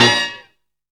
MAD HIT.wav